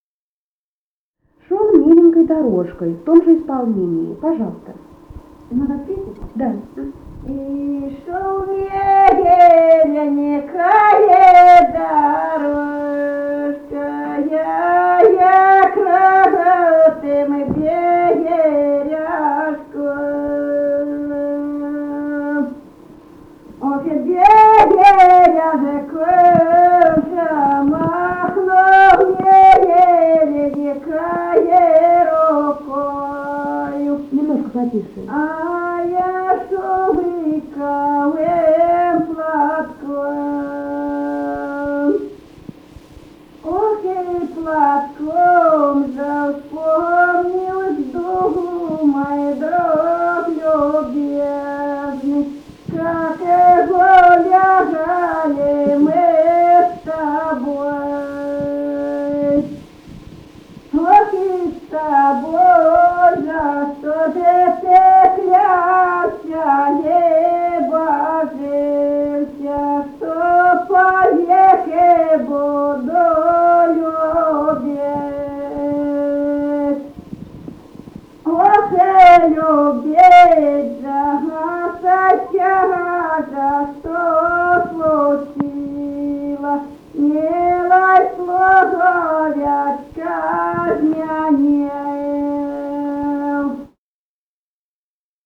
Музыкальный фольклор Климовского района 002. «Ишёл миленький дорожкой» (беседная).